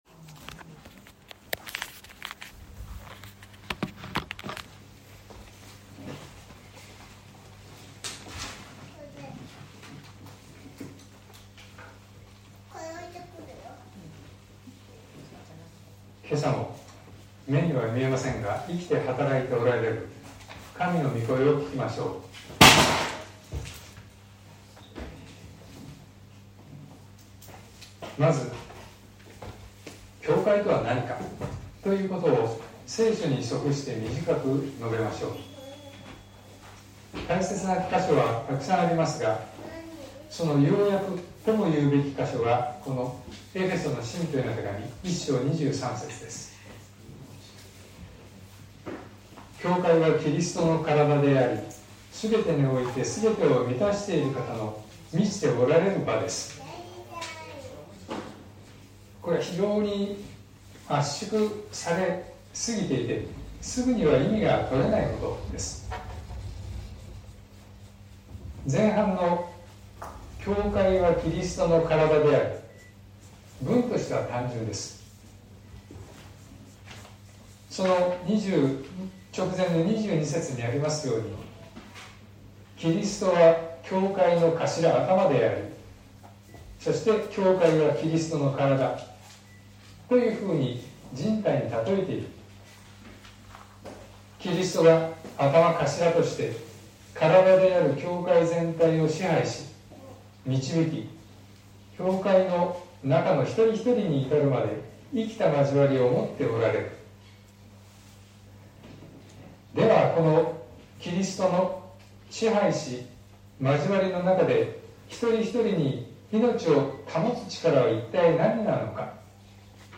2023年10月15日朝の礼拝「教会は生きている」東京教会
説教アーカイブ。